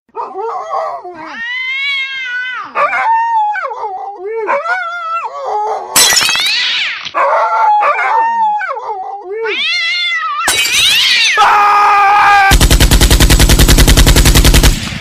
cat-vs-dog-fight.mp3